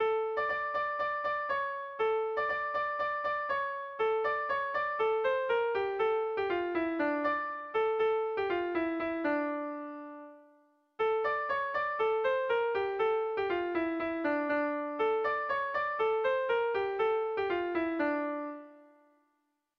Haurrentzakoa
AAB